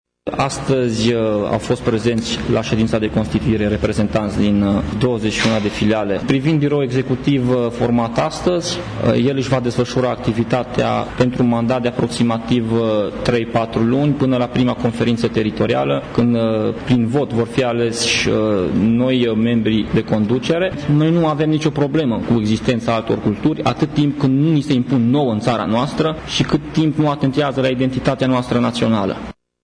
Acesta a declarat, astăzi, într-o conferință de presă că deja există structuri locale ale partidului în 21 de localități mureșene, iar numărul acestora va crește până în toamnă, când va avea loc conferința județeană de alegeri.